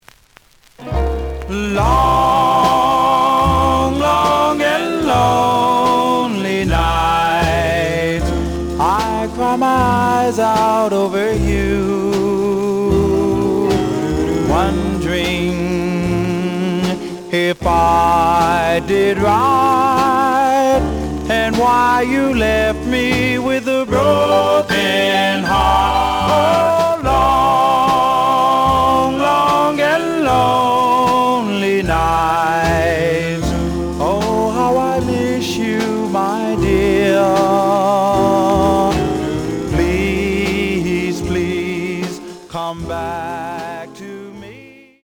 The audio sample is recorded from the actual item.
●Genre: Rhythm And Blues / Rock 'n' Roll
Some click noise on first half of A side due to a bubble.